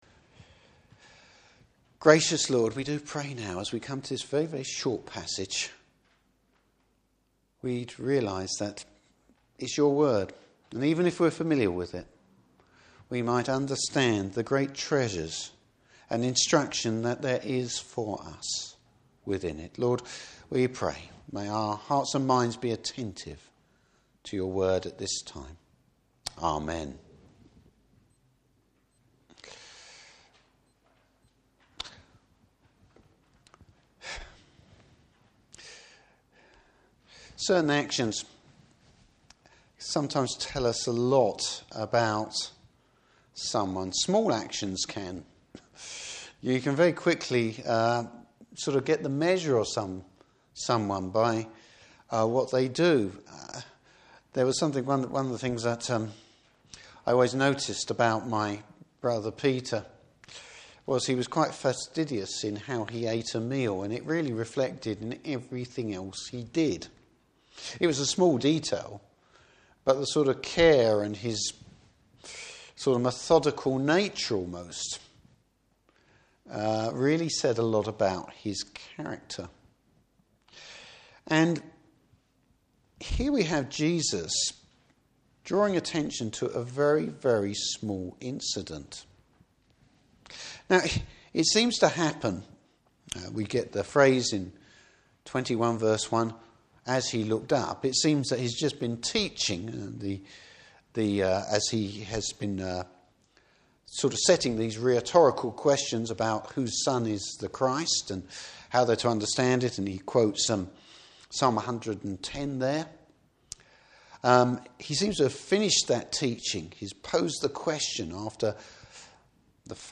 Service Type: Morning Service Jesus draws attention to some real love and devotion!